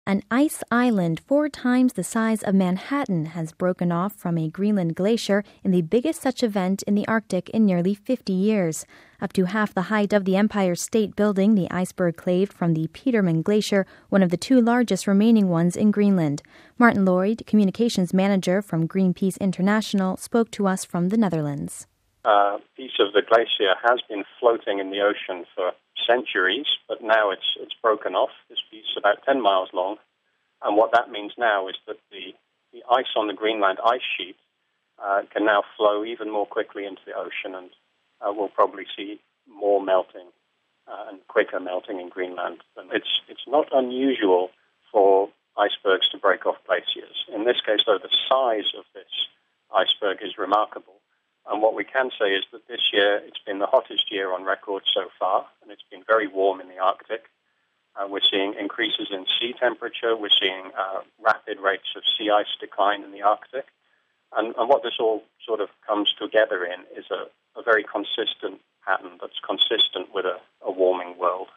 spoke to us from the Netherlands